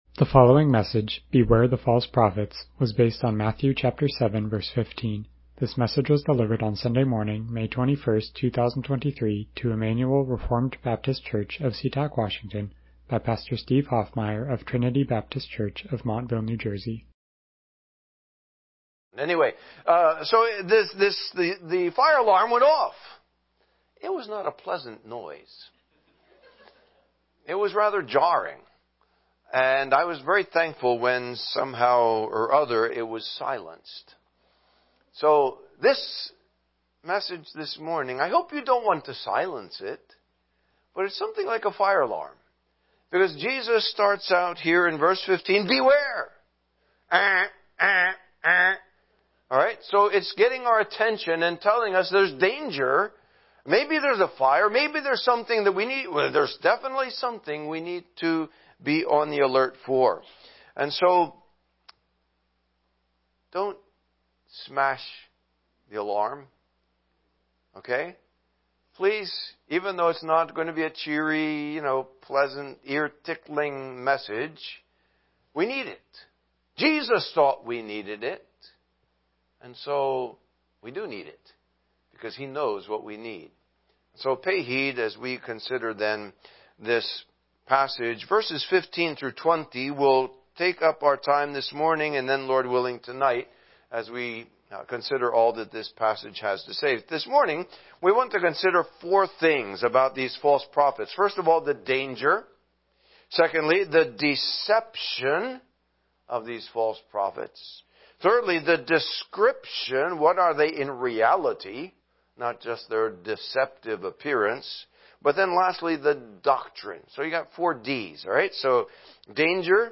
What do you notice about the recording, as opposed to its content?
Matthew 7:15 Service Type: Morning Worship « Biography of Charles Spurgeon